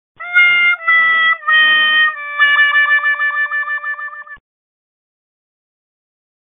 نغمة كوميدية ومضحكة للمسجات – 02